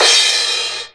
Crash1.wav